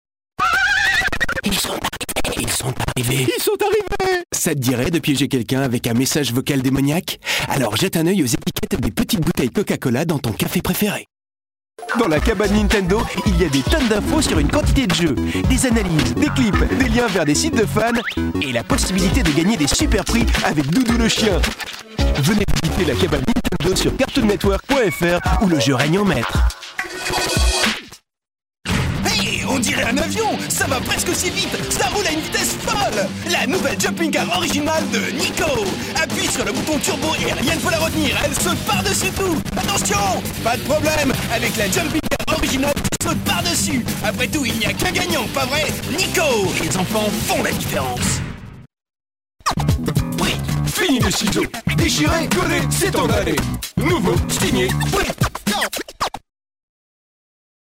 Sprecher französisch
Kein Dialekt
Sprechprobe: eLearning (Muttersprache):
french voice over talent